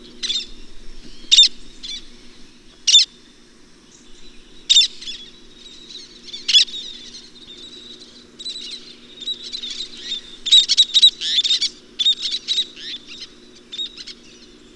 Least Tern
Charrßn Menor,
Sterna antillarum
VOZ: Cuando es molestada en las colonias de crφa, un rechinado "chirip".